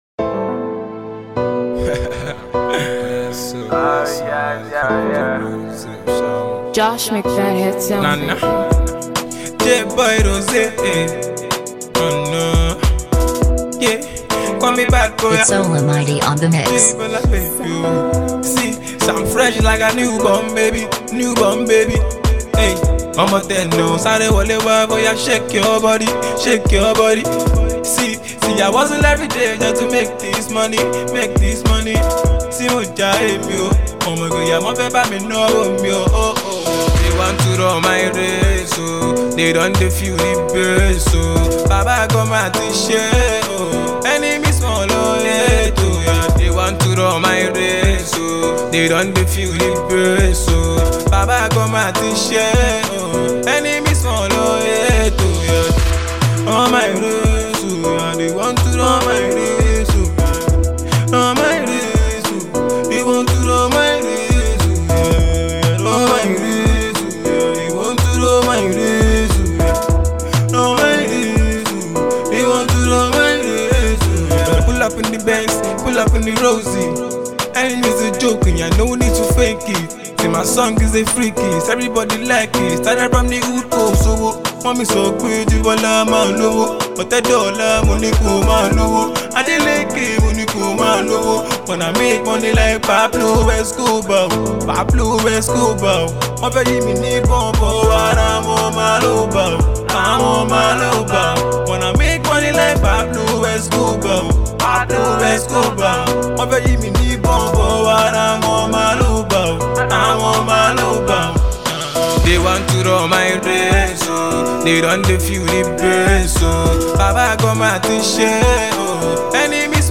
Nigerian talented singer